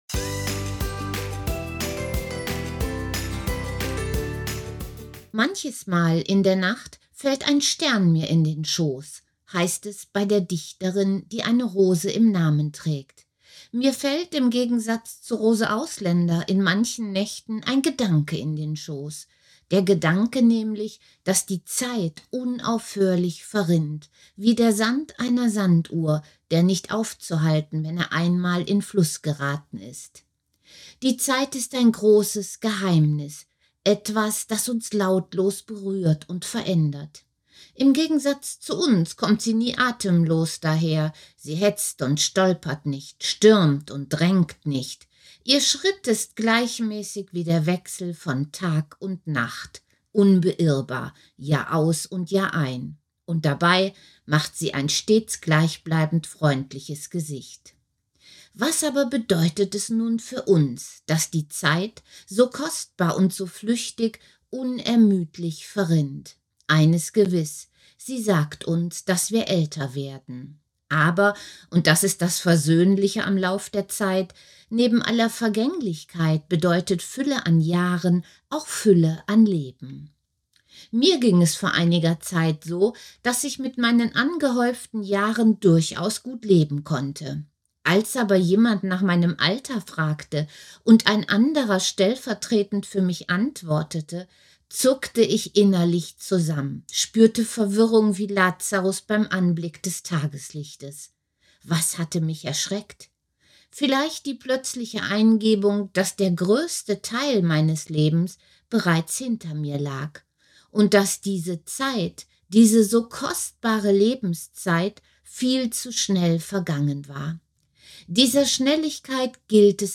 Text als Audiodatei